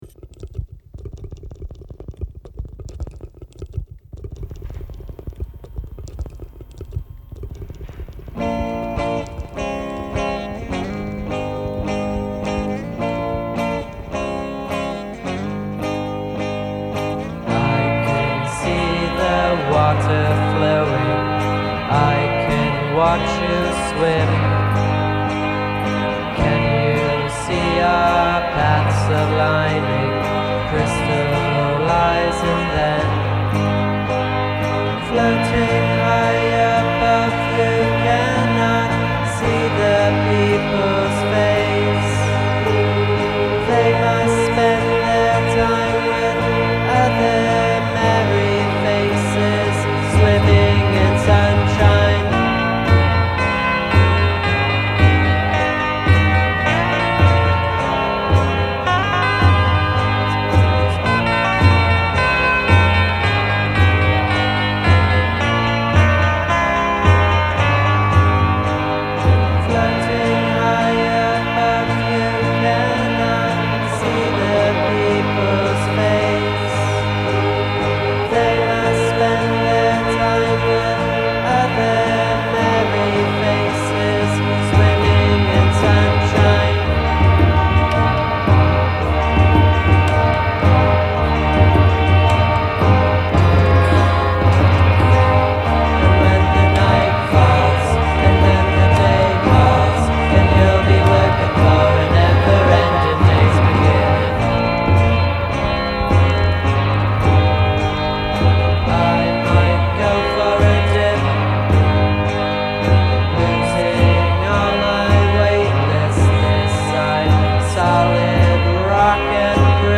psych-pop troupe